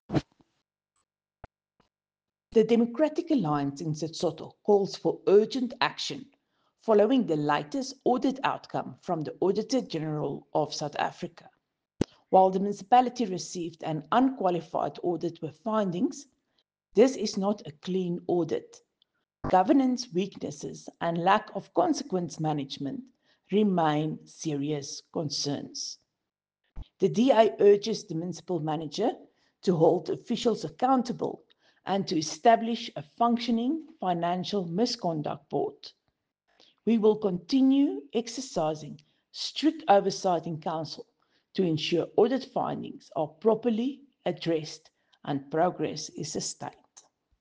Afrikaans soundbites by Cllr Riëtte Dell and Sesotho soundbite by Cllr Tim Mpakathe.